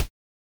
ctf_ranged_ricochet.ogg